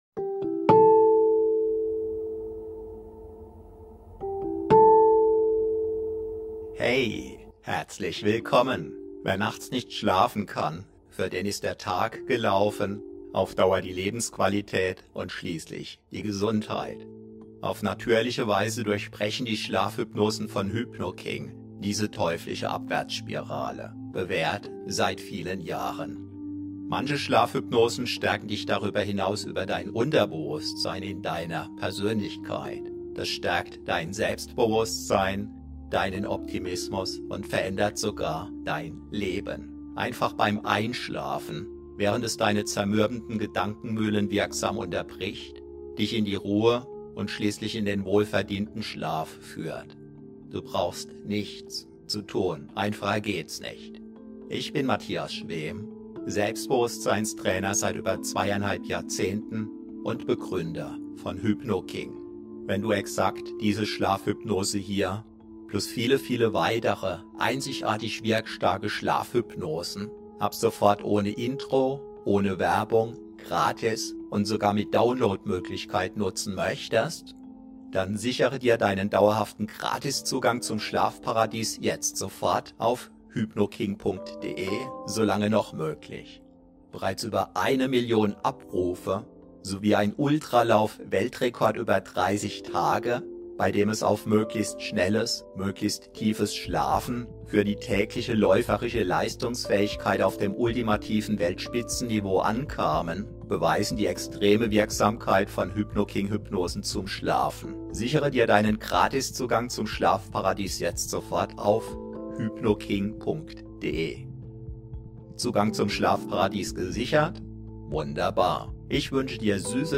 ✨ Schlafhypnose zur Stärkung deines Selbstwerts aus 28 Jahren Hypnose-Praxis - ULTRA STARK!.